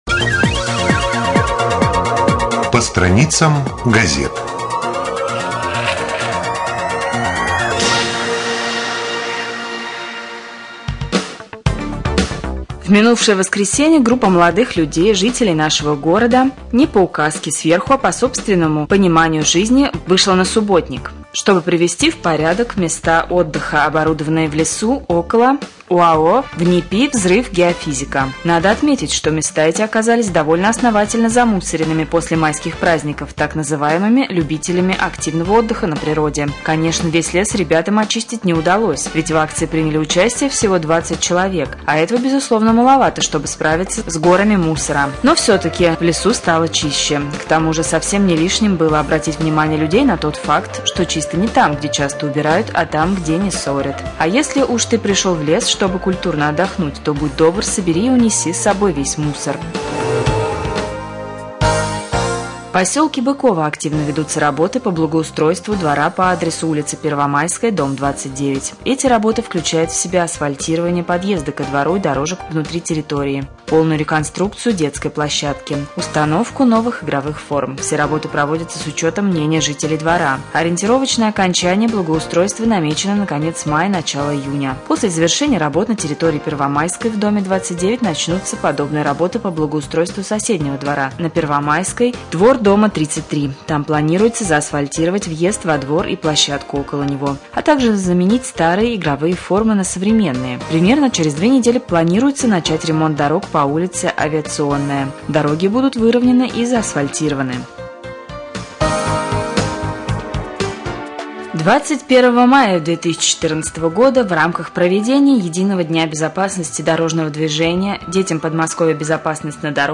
1.Рубрика «По страницам прессы». Новости